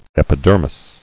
[ep·i·der·mis]